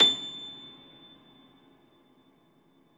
53c-pno25-A5.wav